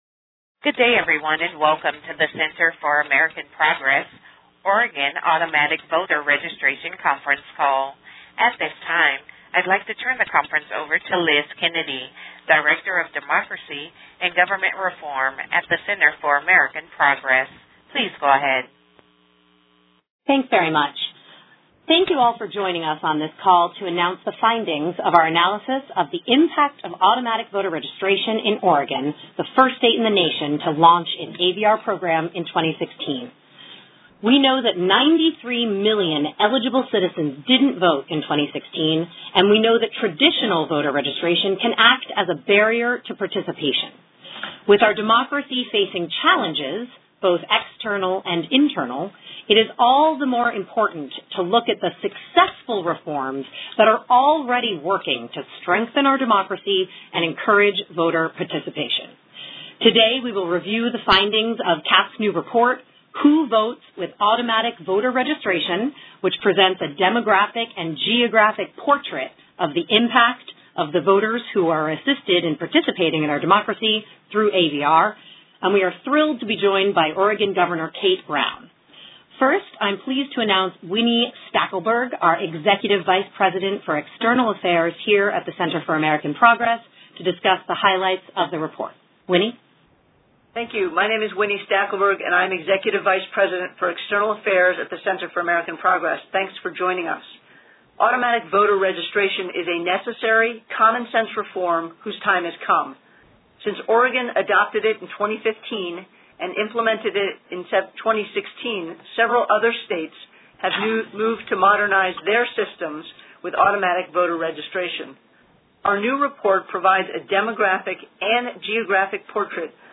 To listen to audio recording of the press call on this topic, featuring Oregon Gov. Kate Brown and voting experts, please click here.